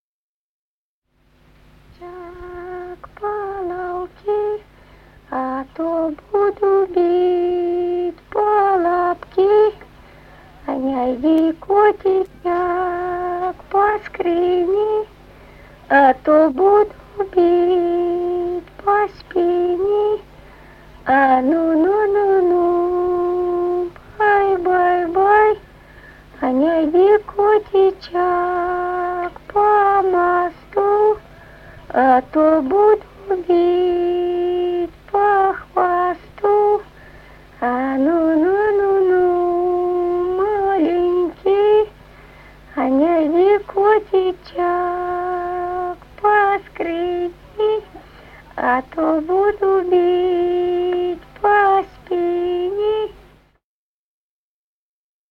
Народные песни Стародубского района «Не йди, котичек», колыбельная.
с. Мишковка.